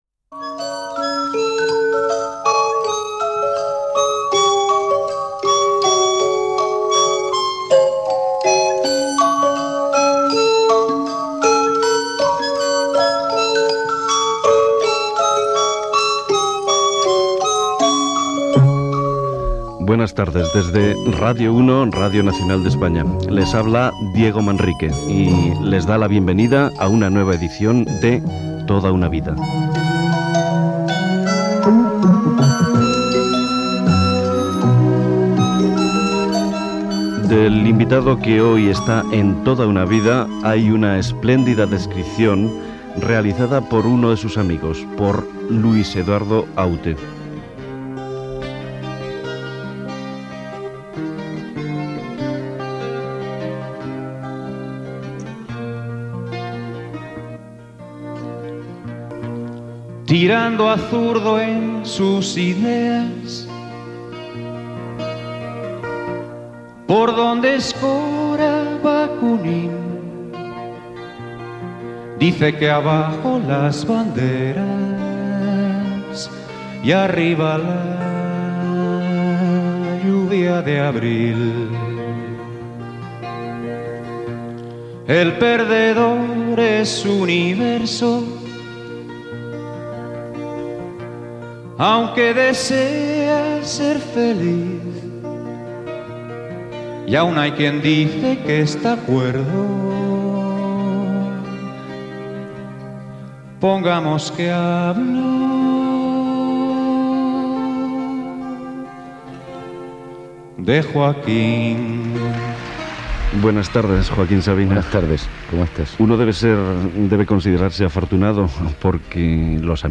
Sintonia, presentació i entrevista al cantant Joaquín Sabina sobre la seva trajectòria personal i professional